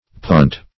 punt - definition of punt - synonyms, pronunciation, spelling from Free Dictionary